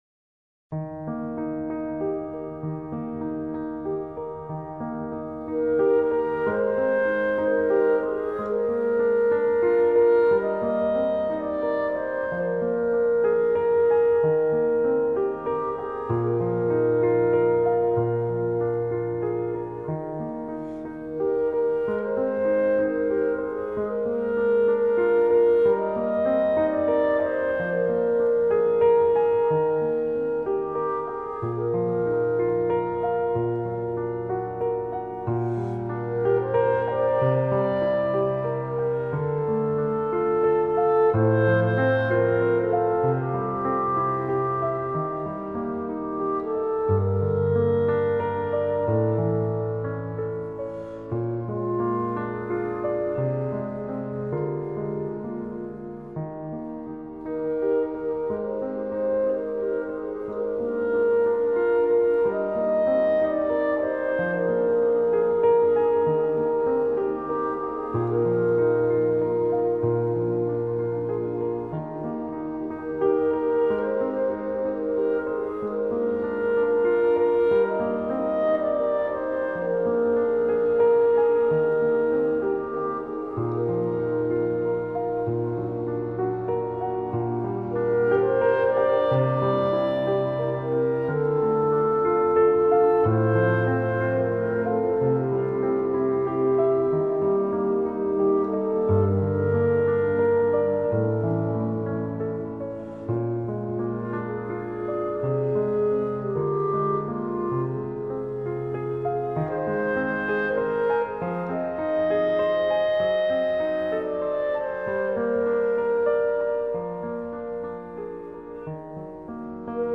在这张专辑里，你将会听到许多优秀的钢琴乐。